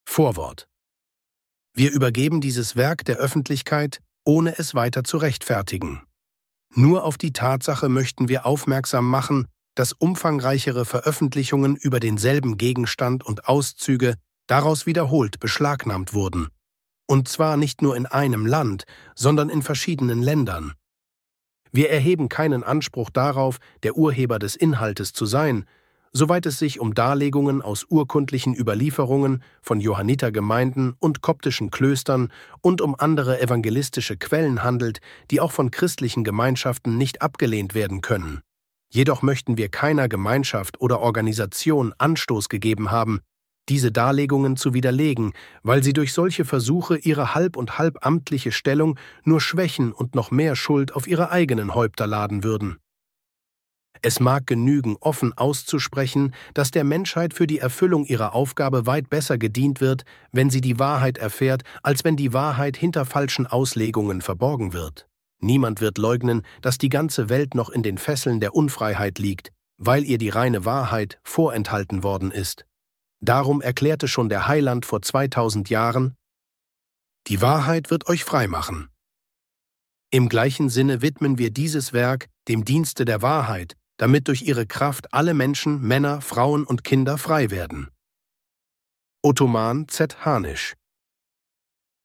Yehoshua - Das Leben Jesu - Hörbuch